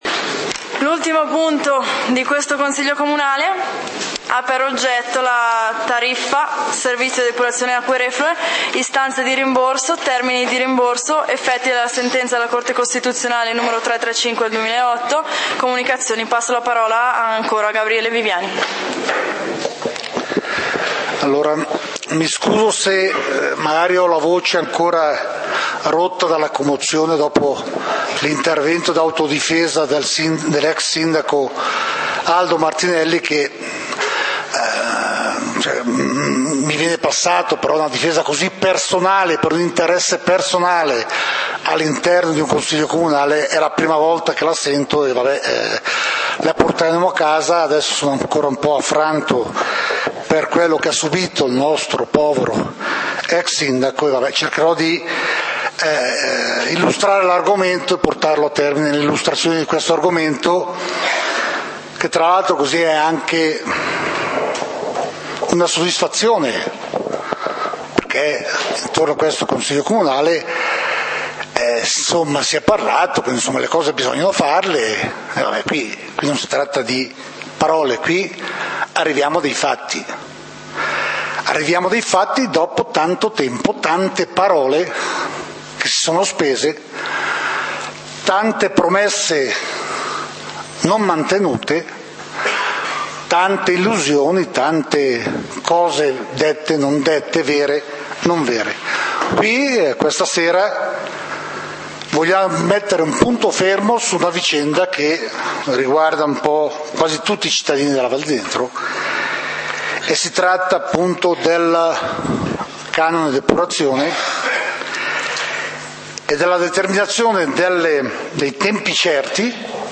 Punti del consiglio comunale di Valdidentro del 27 Settembre 2012